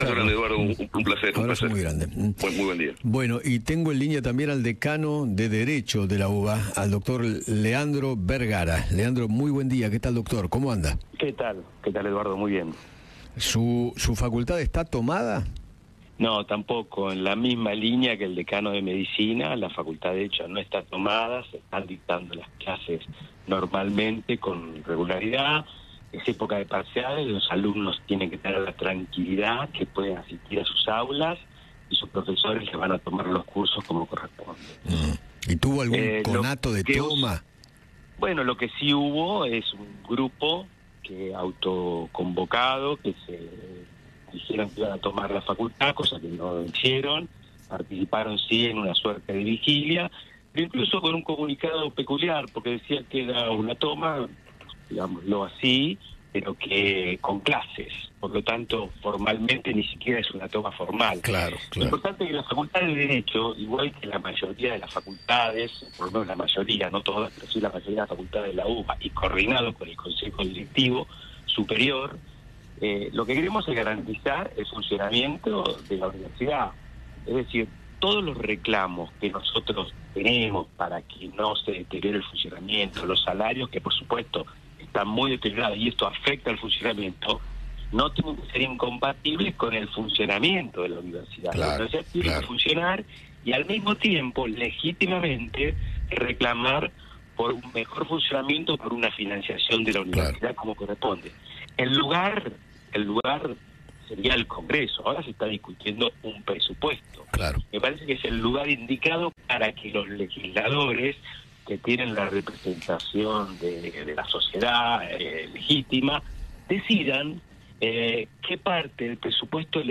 Eduardo Feinmann conversó con